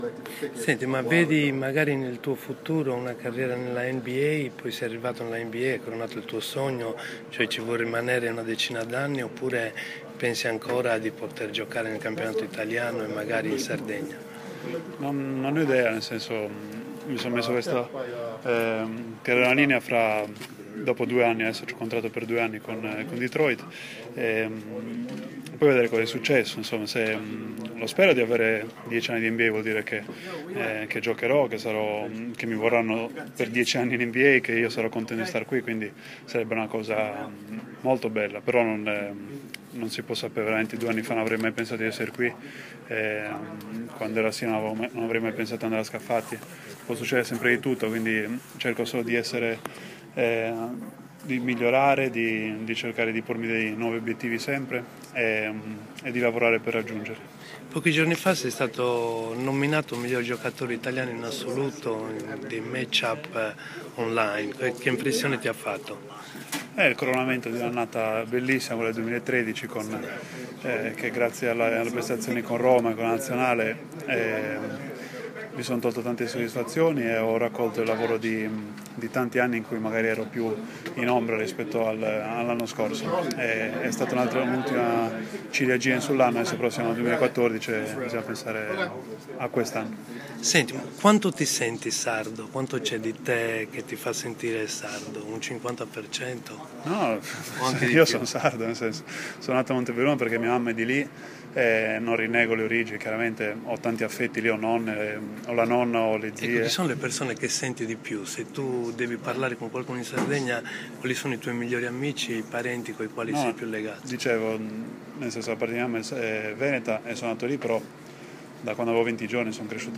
Ho atteso a New York un mese che arrivasse l’ok dalla NBA per poterlo incontrare negli spogliatoi del Madison Square Garden prima del suo incontro coi Knicks e lui, con gentilezza e professionalità mi ha parlato di questo suo momento, della sua vita, la sua carriera, la sua sardità il suo dolore per l’alluvione a Olbia.